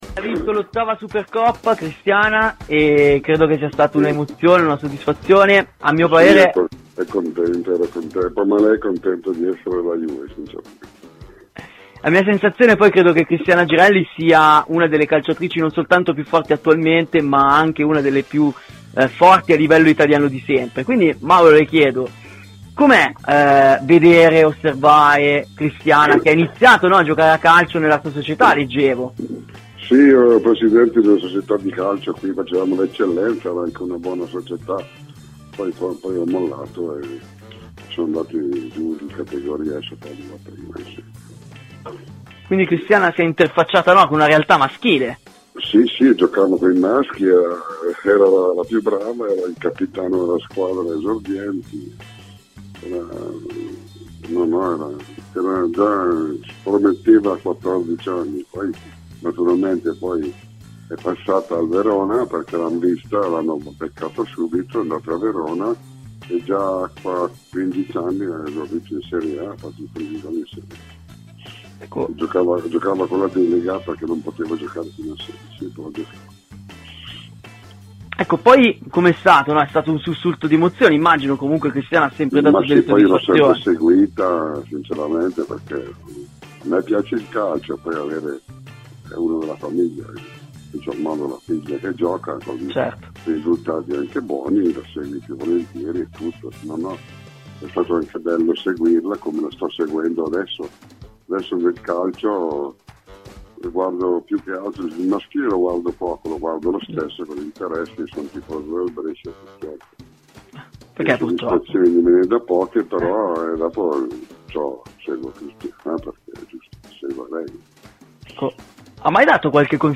intervistato
ai microfoni di "Terzo Tempo" © registrazione di Radio Bianconera